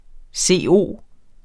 CO symbol Udtale [ ˈseˀˈoˀ ] Betydninger kemisk formel for kulilte Rapportér et problem fra Den Danske Ordbog Den Danske Ordbog .